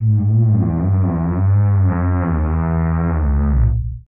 MOAN EL 03.wav